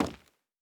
PavementTiles_Mono_01.wav